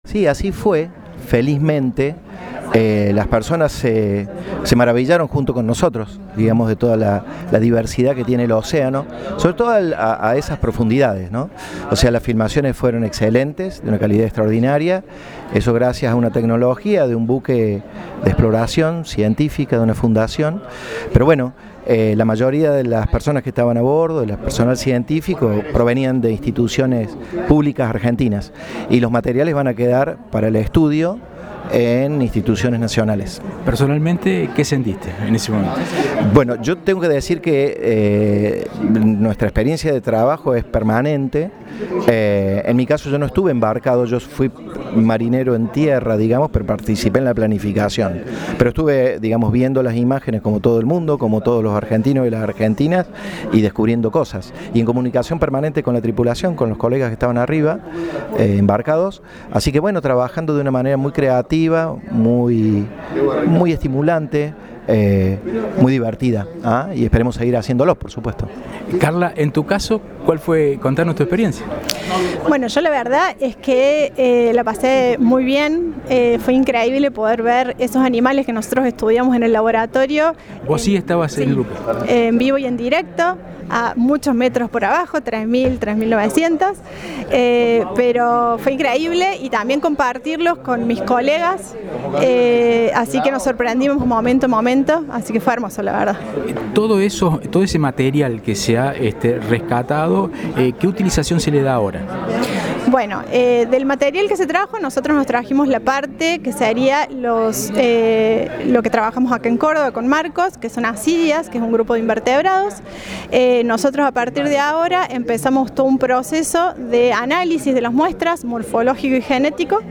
Científicos Cordobeses